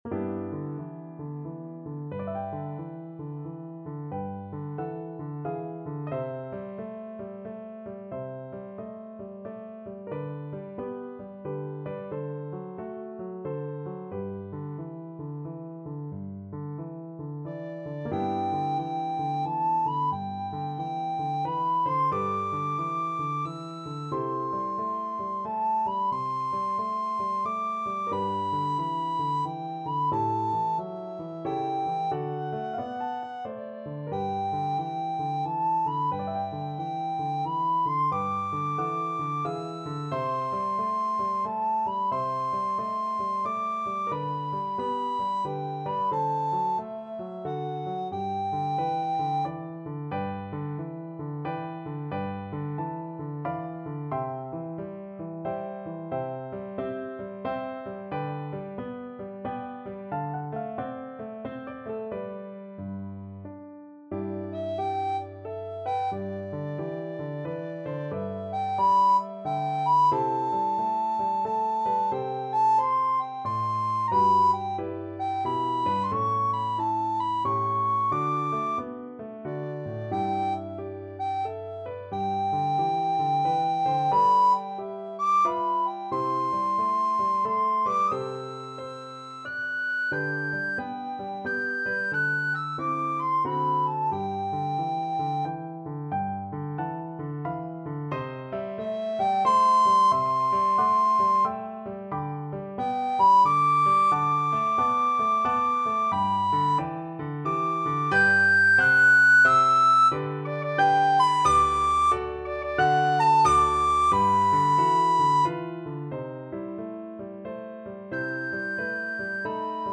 Soprano (Descant) Recorder version
3/4 (View more 3/4 Music)
Relaxed Swing =c.90
Recorder  (View more Easy Recorder Music)